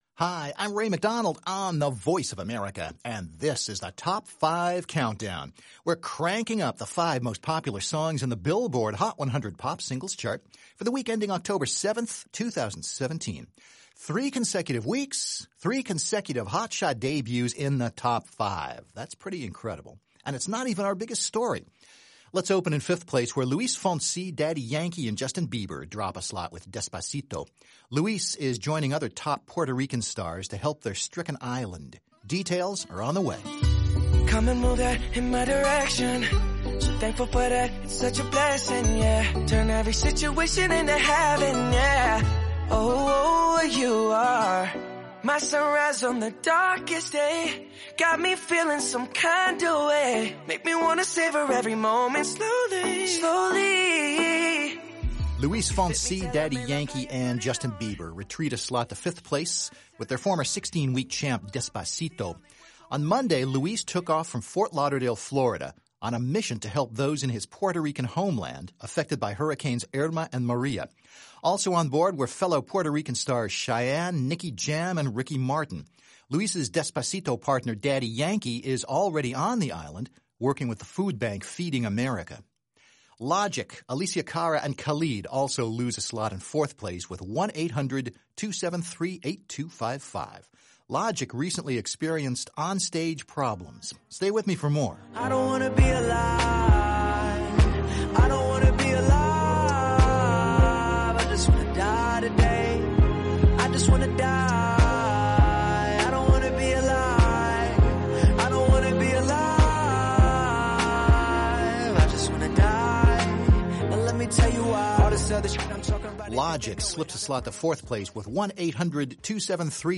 Top 5 Countdown for Week Ending Oct. 7